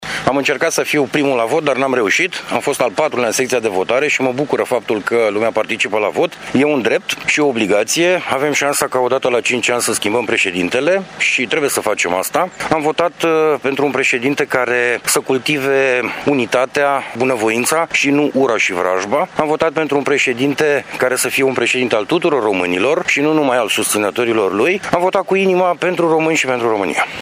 Între cei care au votat de dimineață s-a numărat și președintele Comisiei pentru sănătate și familie, deputatul social-democrat Florin Buicu: